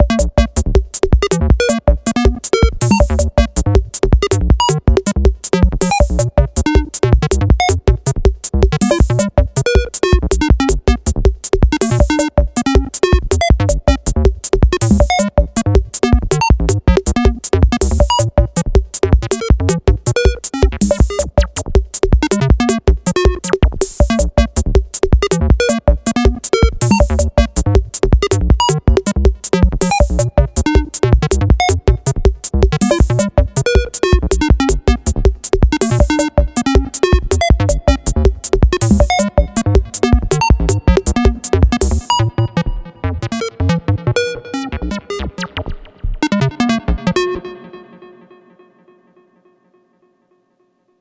Roland SPV-355 Synthesizer / Voltage-Controlled Oscillator
TEISCO SR-900 Multi Effector
Moog VX-351 Option